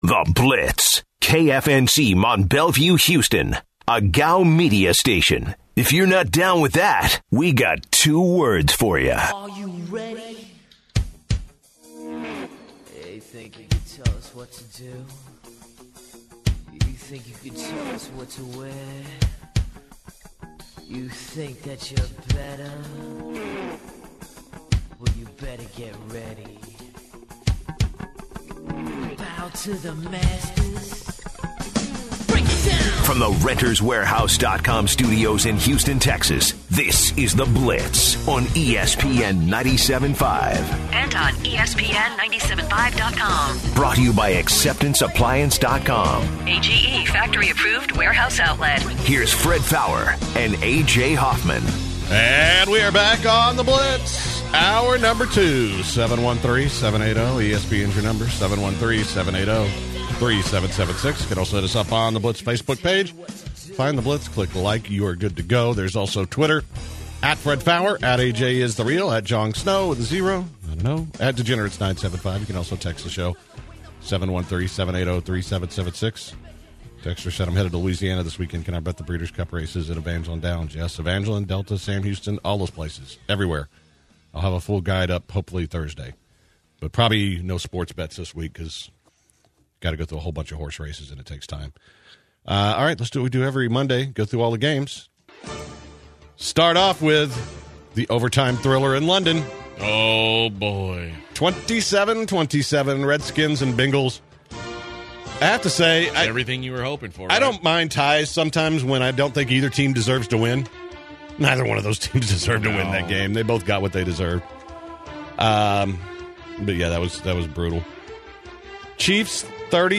The guys cover all of the NFL games from the weekend and preview the game between the Bears and Vikings on Monday night. Stanford Routt joins the show to discuss the Texans. The Zadok Jeweler Gem of the Day includes a fart.